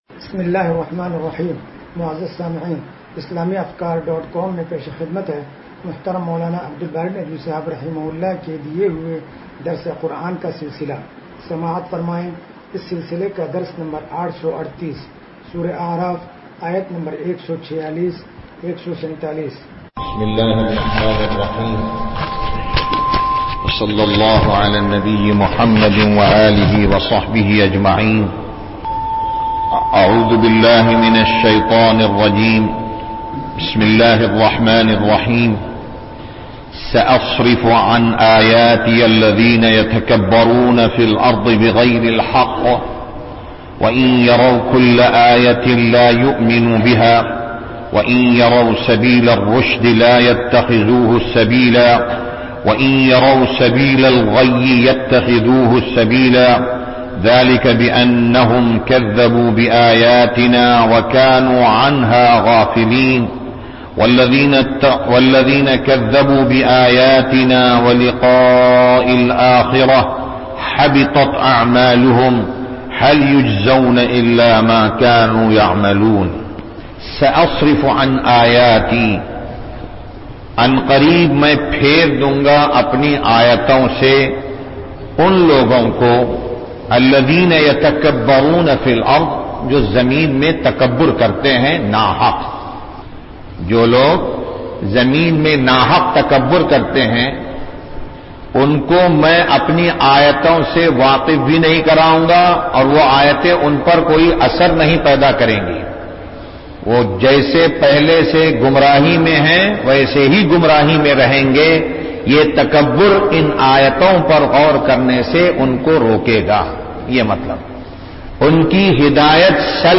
درس قرآن نمبر 0838